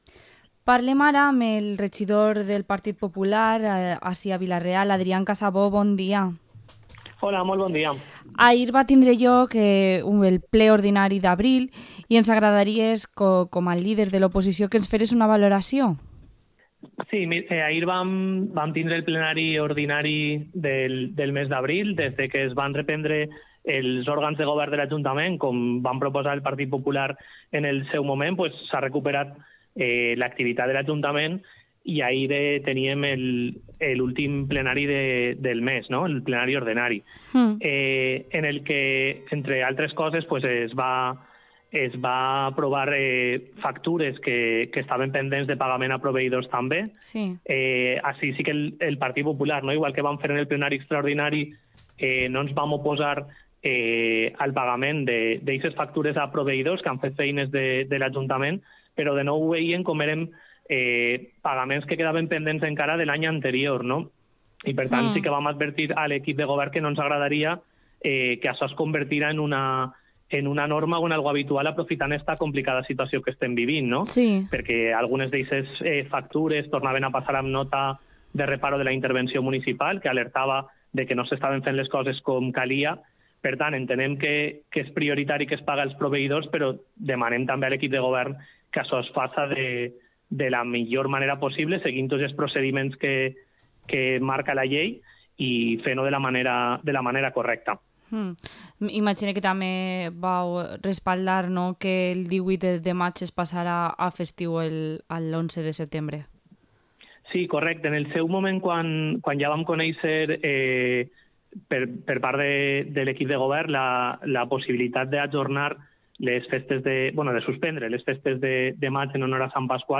Entrevista al concejal del PP de Vila-real, Adrian Casabó